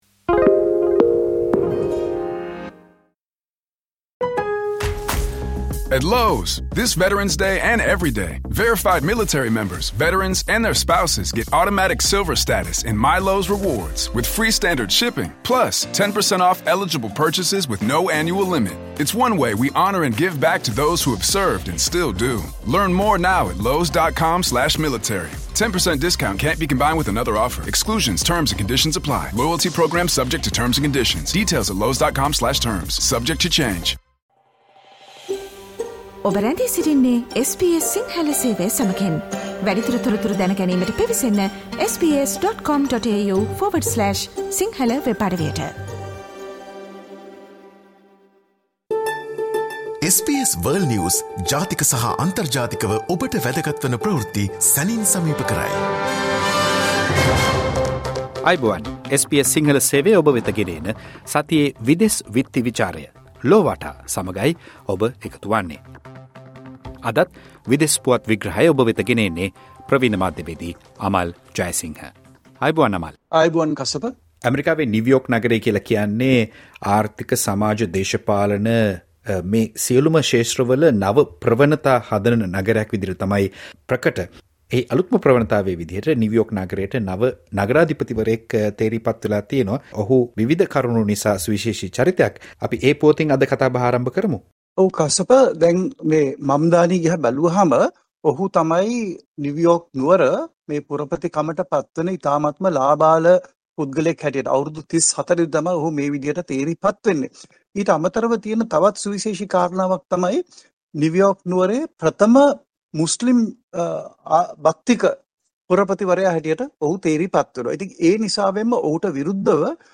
සතියේ විදෙස් පුවත් විග්‍රහය